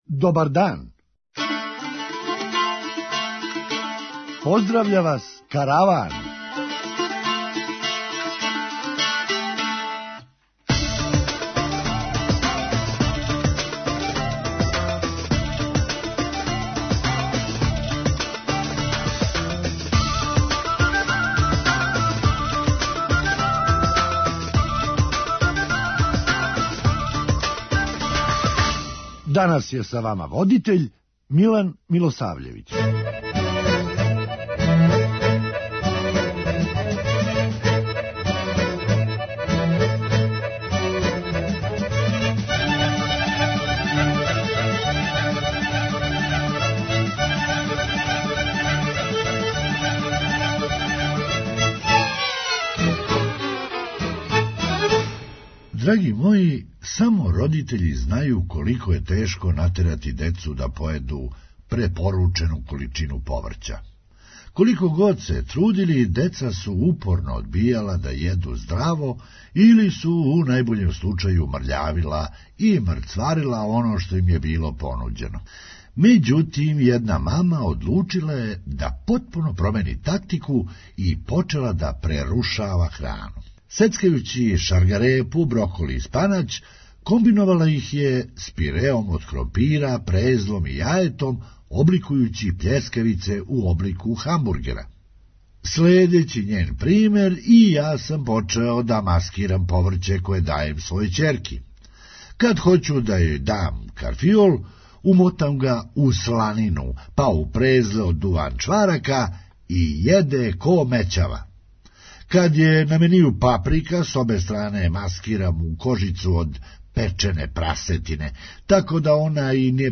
Хумористичка емисија
Ето, новине пишу да је високом представнику за БиХ, Кристијану Шмиту, ове недеље све прекипело. преузми : 8.97 MB Караван Autor: Забавна редакција Радио Бeограда 1 Караван се креће ка својој дестинацији већ више од 50 година, увек добро натоварен актуелним хумором и изворним народним песмама.